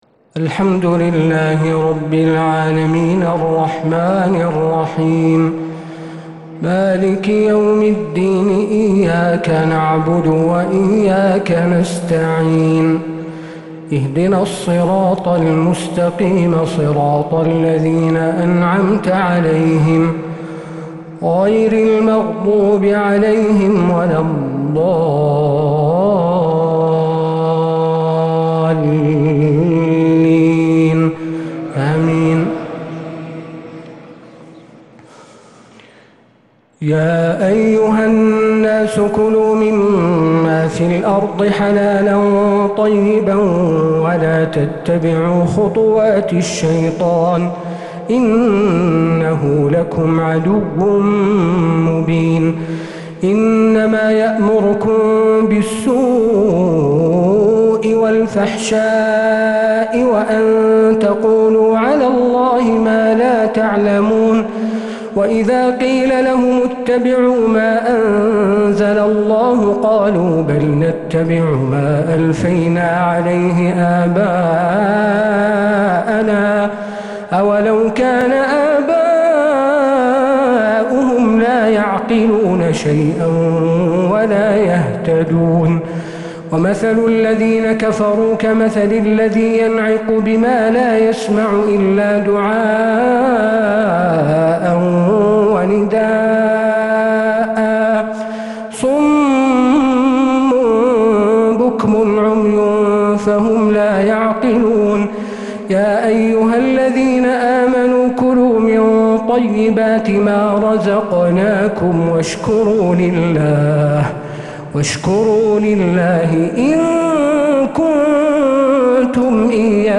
تراويح ليلة 2 رمضان 1446هـ من سورة البقرة ( 168-203 ) | Taraweeh 2nd night Ramadan 1446H > تراويح الحرم النبوي عام 1446 🕌 > التراويح - تلاوات الحرمين